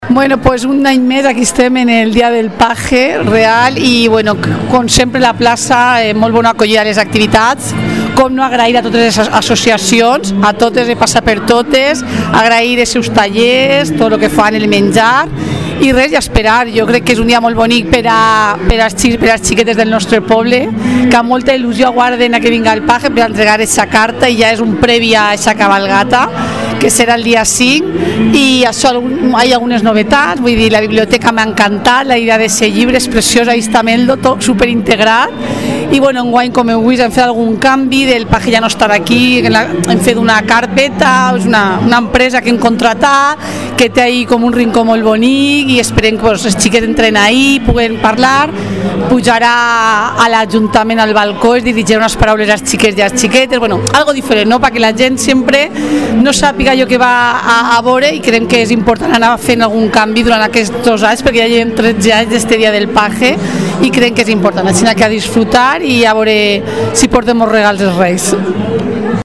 Silvia Verdú, edil de Cultura y Juventud